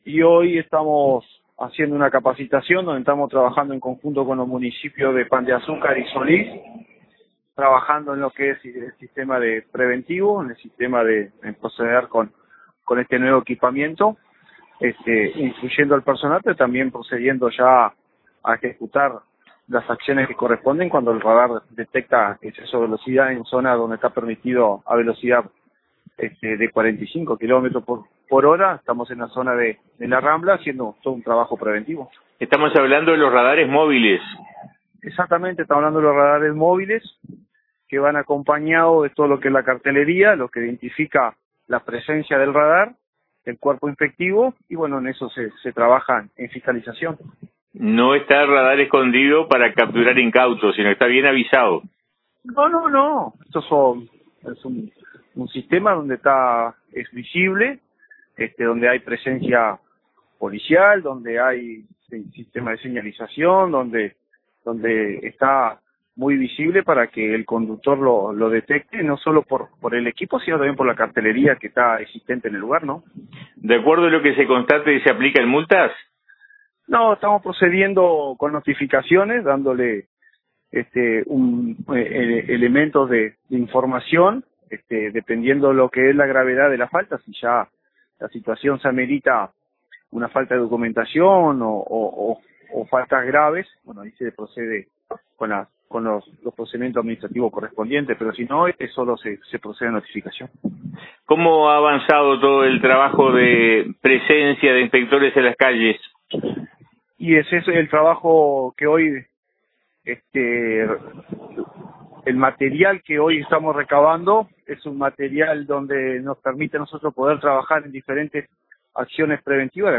dijo en charla con RADIO RBC